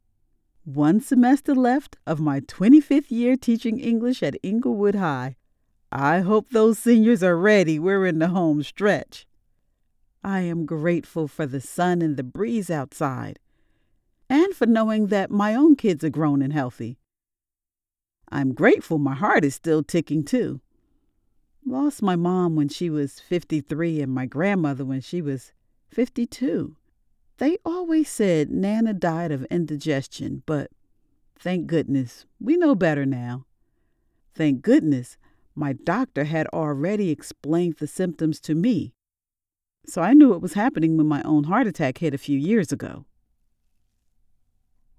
Warm and Engaging Voice
Corporate Pitch
Northeast
Middle Aged